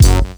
GUnit Synth4.wav